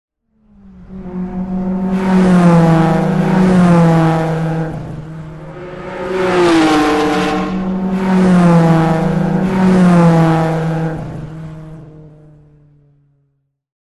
Звуки гонок
Шум гонки, когда машины проносятся слева направо